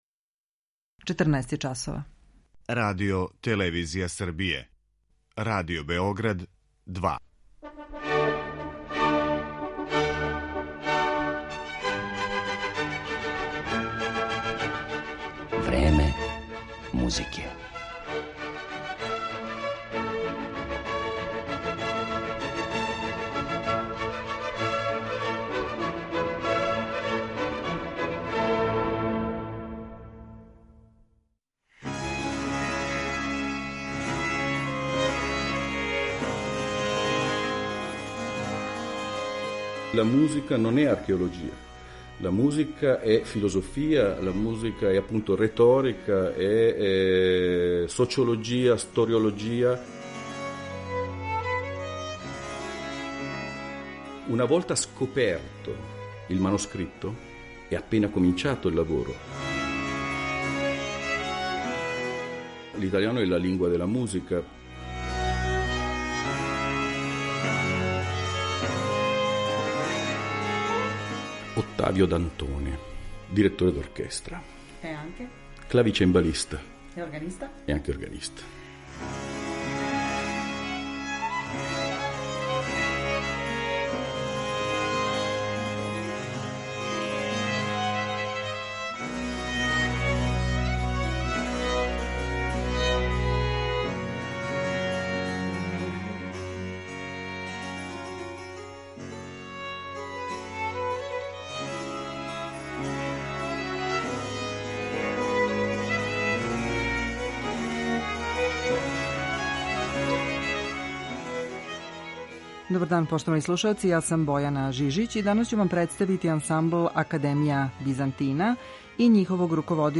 У данашњој емисији слушаћете један од најбољих италијанских барокних ансамбала „Академија Бизантина”, који посебну пажњу посвећује мање познатим и ређе извођеним италијанским мајсторима XVII и XVIII века.
Поред изврсног музицирања ових уметника, у емисији ћете моћи да чујете и ексклузивни интервју са руководиоцем ансамбла, чембалистом, оргуљашем и диригентом Отавијом Дантонеом, остварен септембра 2019. године у Букурешту.